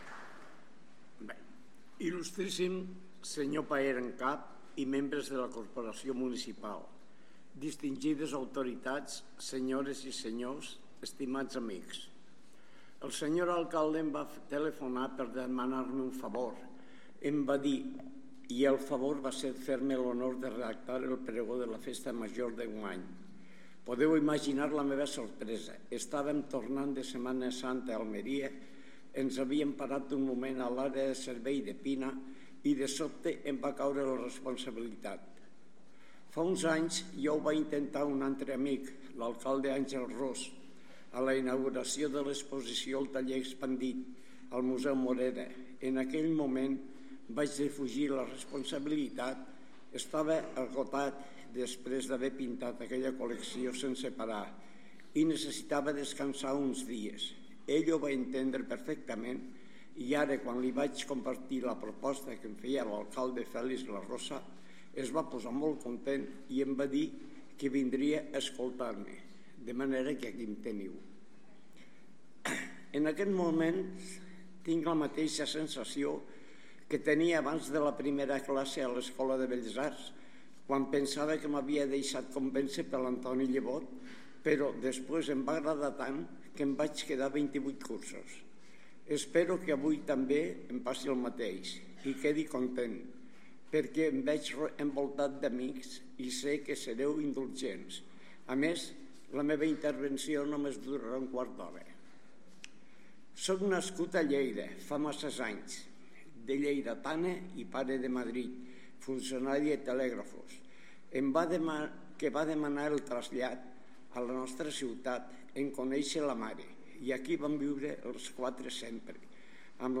Pregó oficial de la Festa Major de Lleida 2024